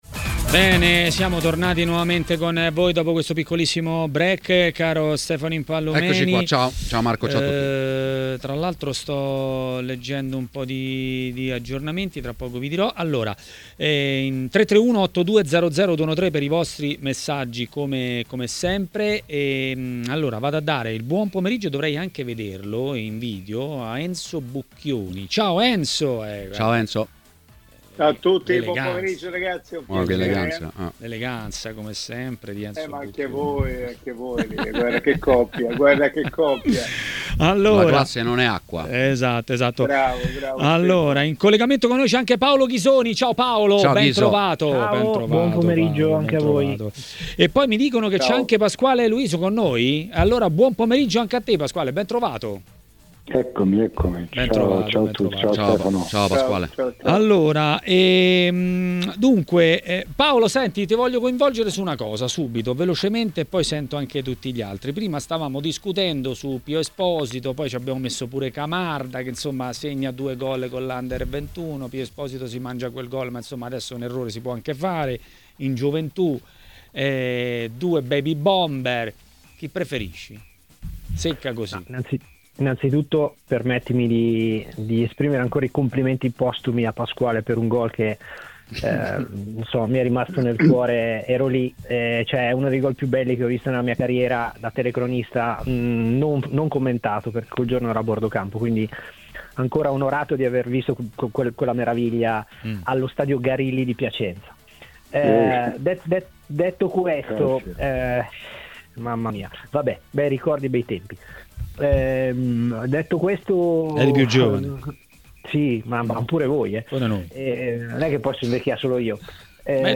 A TMW Radio, durante Maracanà, è intervenuto l'ex calciatore Pasquale Luiso.